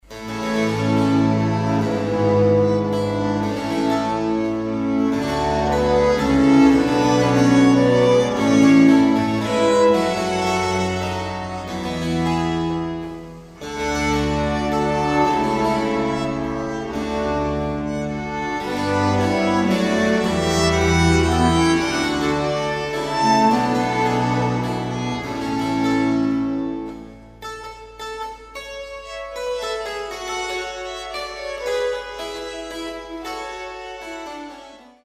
Baroque chamber ensembles
suites (6) for violin, 2 violas & continuo